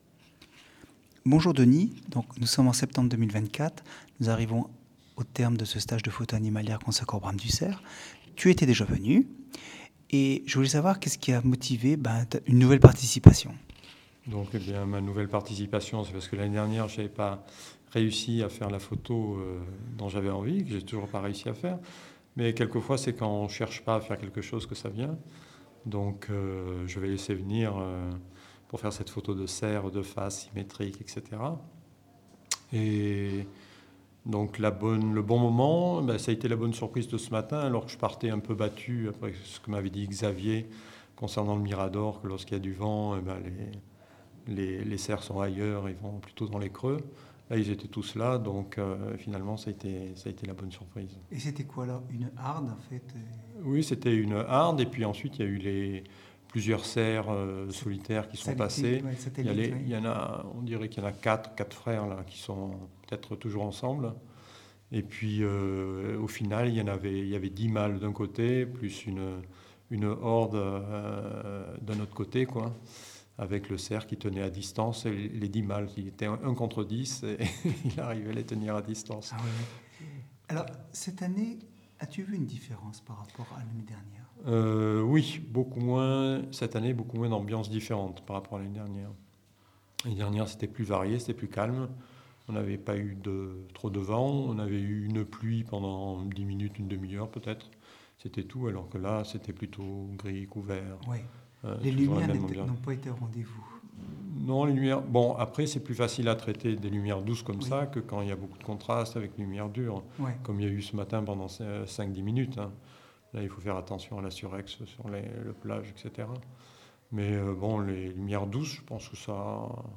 Le commentaire oral des participants